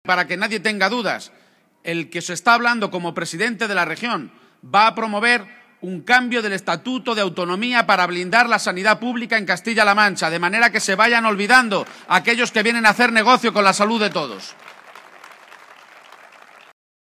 Audio Page en Villarrobledo 1